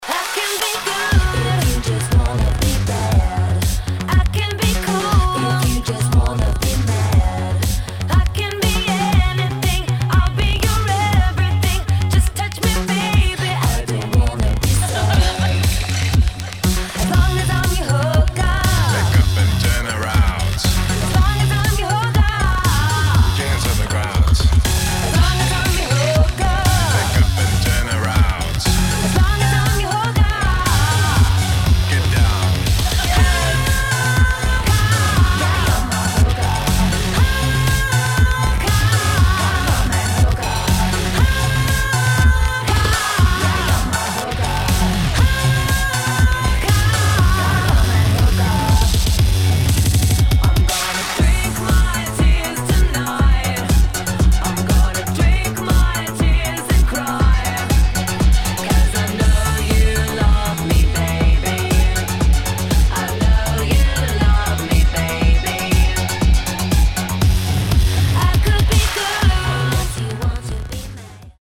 [ POP | HOUSE | DISCO ]